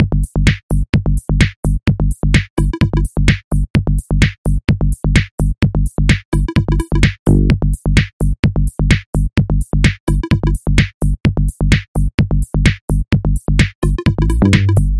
1.16 GROOVY BEAT and STEMS " 1.16 GROOVE BEAT
描述：Iv`e做了1/16 groovy beat plus stems。